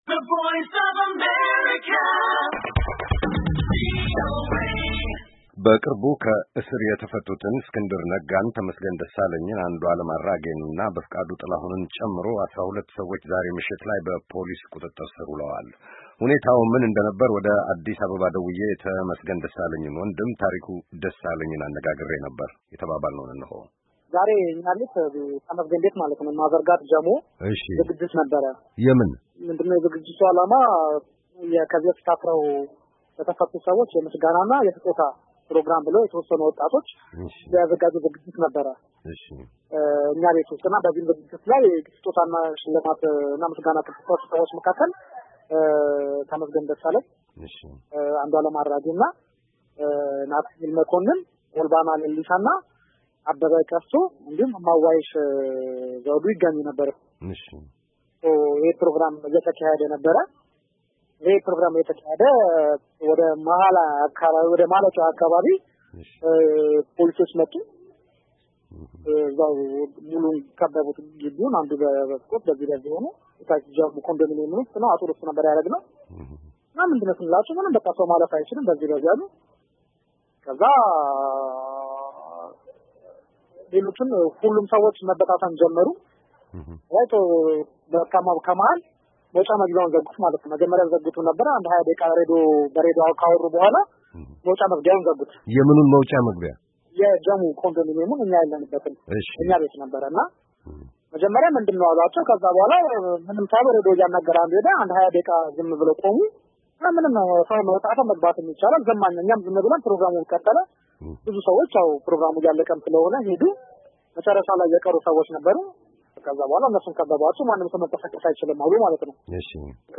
ይህ ዘገባ በአሜሪካ ድምፅ ለአየር እስከበቃበት ጊዜ ድረስ ሁሉም እሥረኞች እዚያው ንፋስ ስልክ ላፍቶ ክፍለ-ከተማ ውስጥ በሚገኘው ጃሞ ፖሊስ ጣቢያ ውስጥ የነበሩ ሲሆን ፖሊስን ለማግኘት እያደረግን ያለነው ጥረት እንደተሣካ ተጨማሪ መረጃ እናቀርባለን።